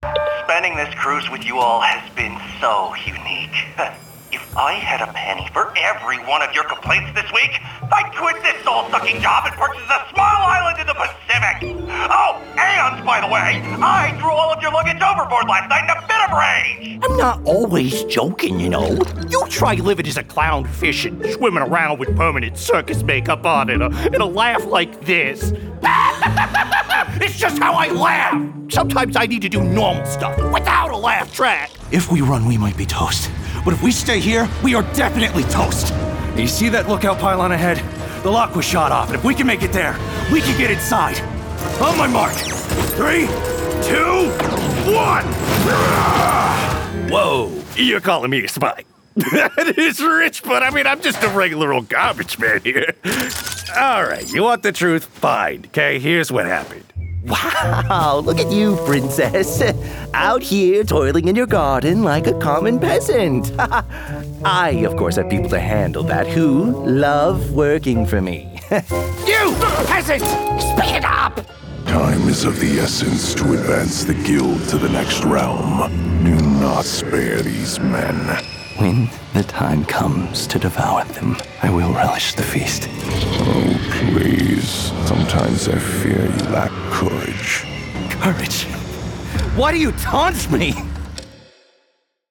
Young Adult, Adult
eastern european | character
new york | character
russian | character
ANIMATION 🎬
husky